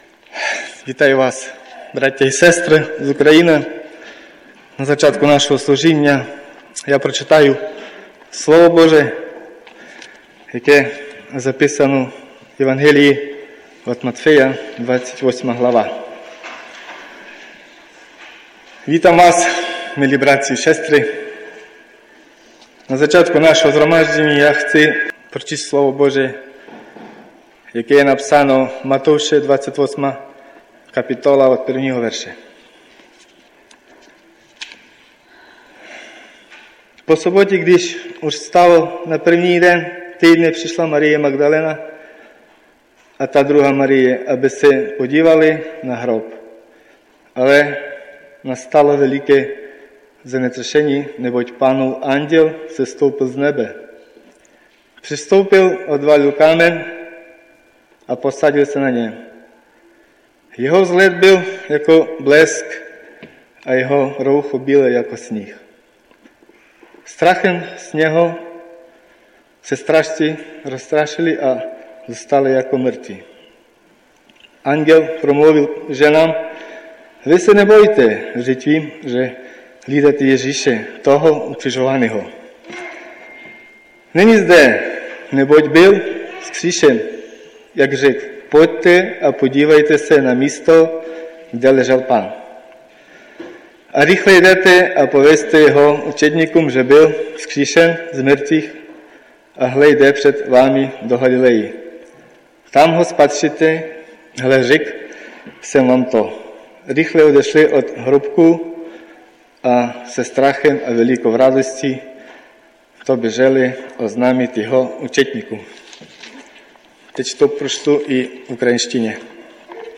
Ukrajinská bohoslužba